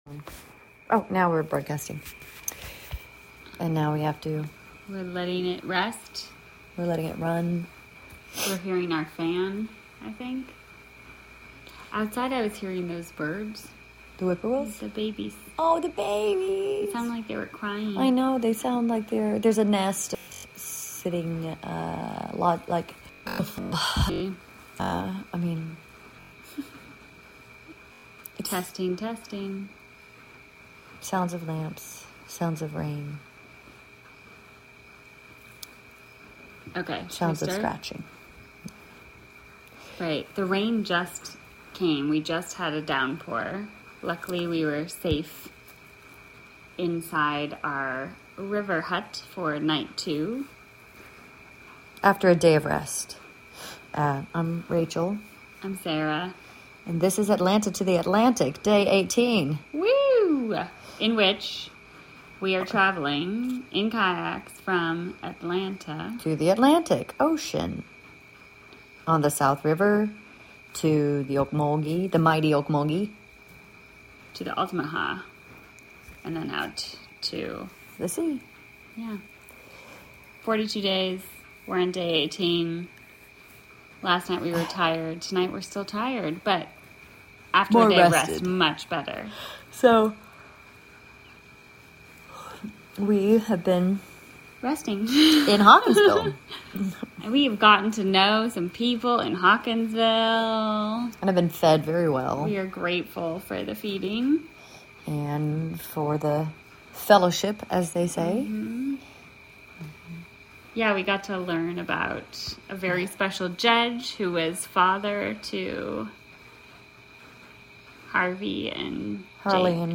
Live from Flux Projects